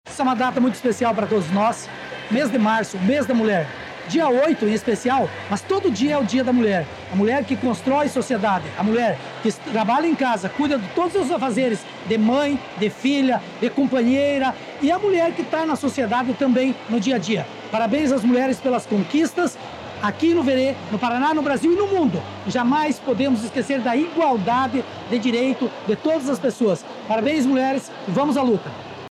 -1o-ENCONTRO-DA-MULHER-VEREENSE-PRONUNCIAMENTO-DO-VICE-PREFEITO_MP3.mp3